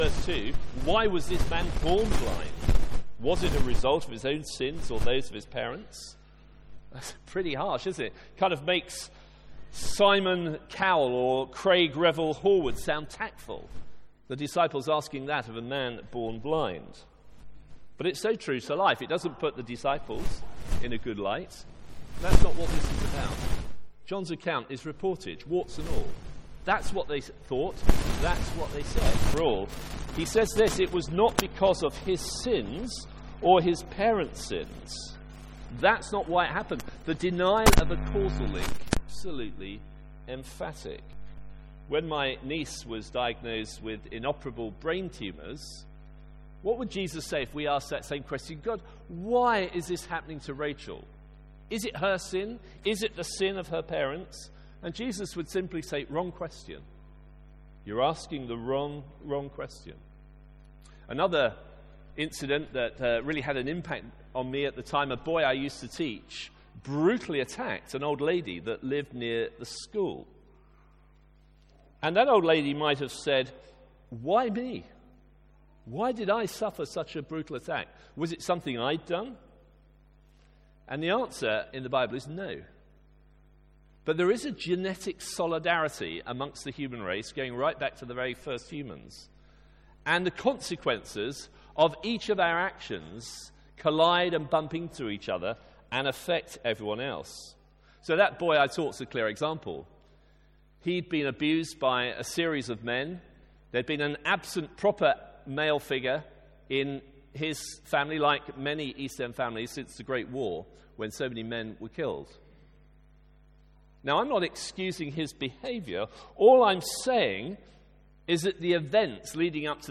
Sermons | St Andrews Free Church
From our guest service before the Christian Union events week.